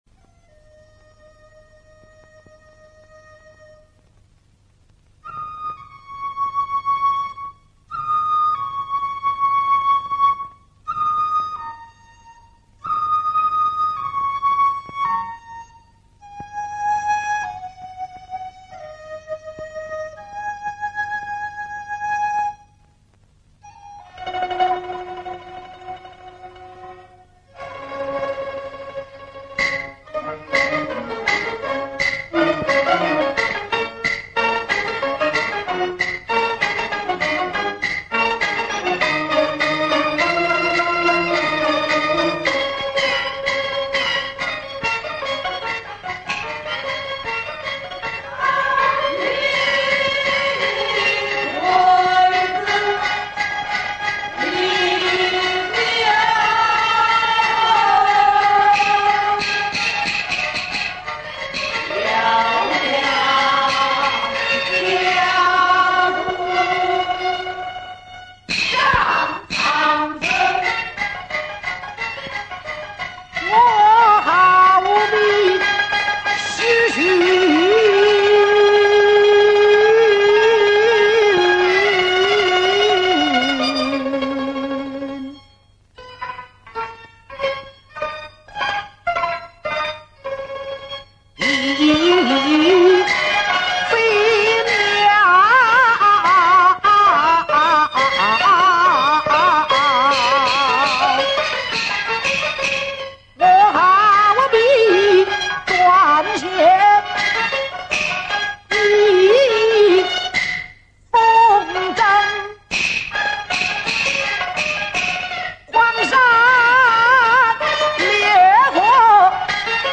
类 型： 舞台艺术片